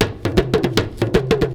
PERC 19.AI.wav